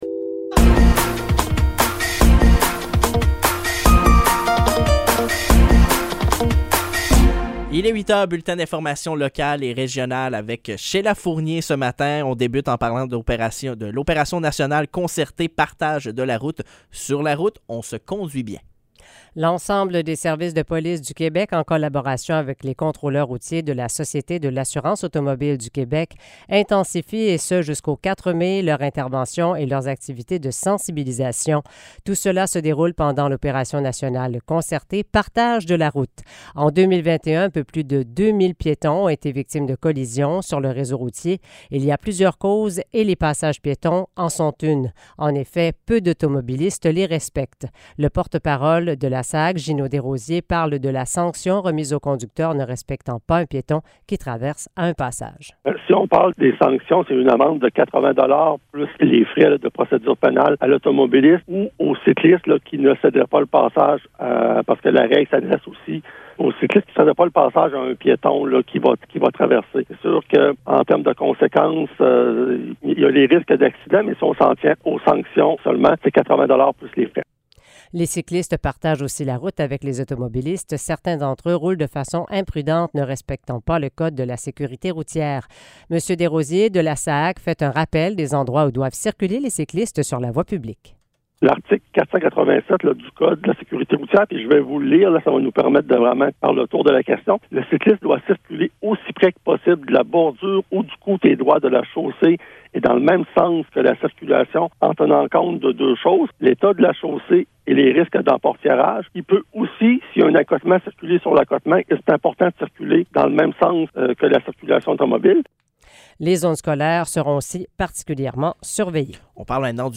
Nouvelles locales - 2 mai 2023 - 8 h